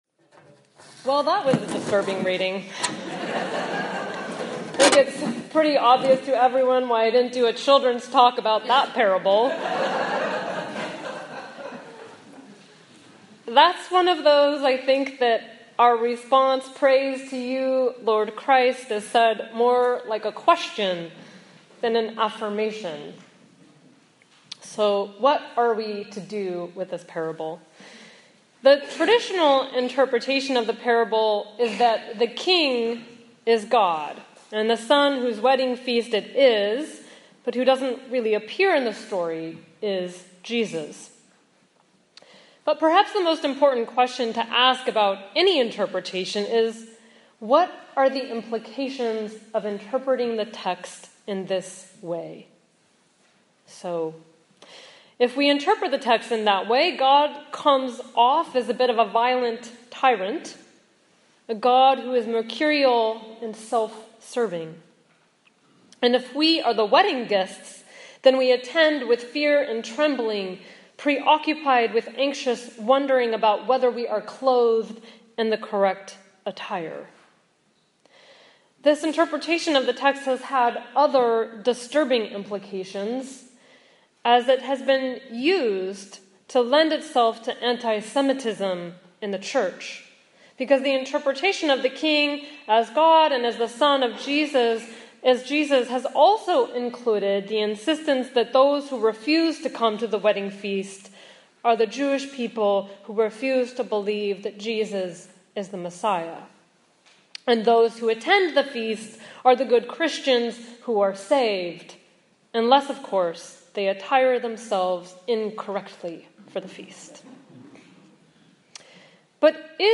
Sermons | St. George's Anglican Church, Cadboro Bay